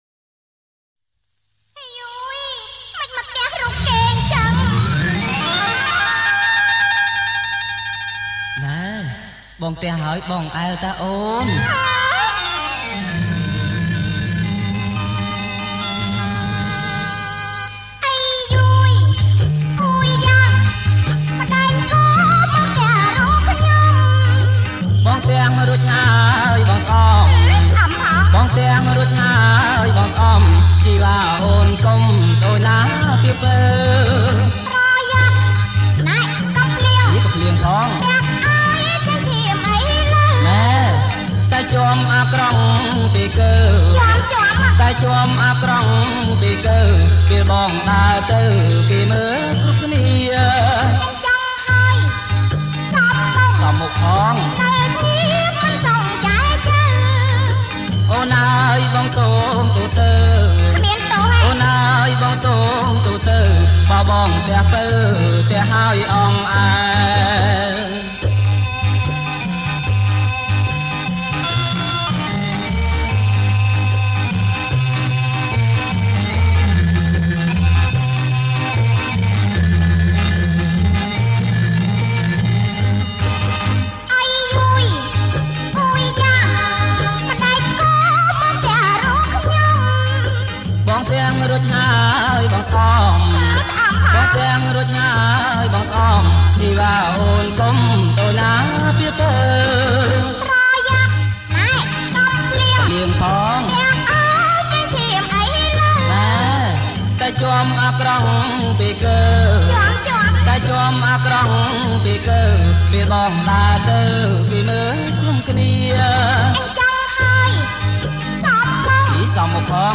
• ប្រគំជាចង្វាក់  រាំវង់